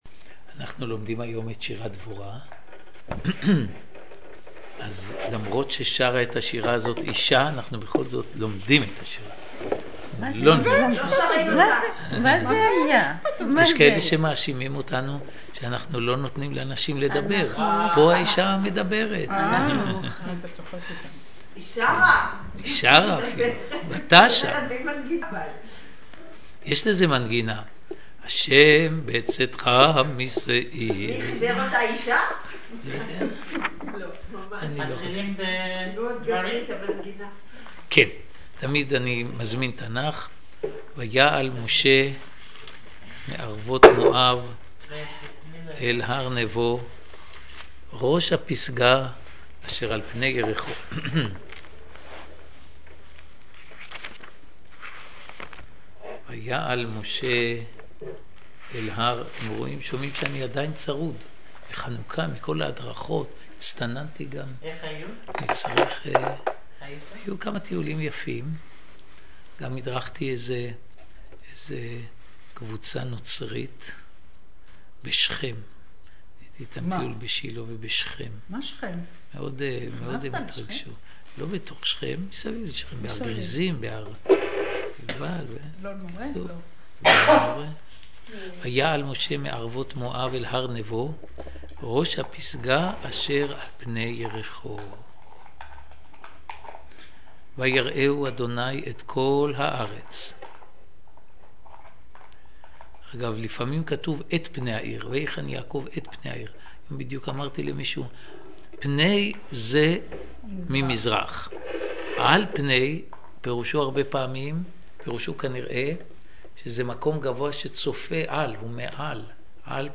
שיעורו